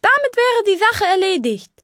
Fallout 3: Audiodialoge
Maleuniquebiwwy_genericcus_combattonormal_0006fe4a.ogg